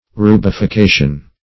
Search Result for " rubification" : The Collaborative International Dictionary of English v.0.48: Rubification \Ru`bi*fi*ca"tion\, n. [Cf. F. rubification.] The act of making red.
rubification.mp3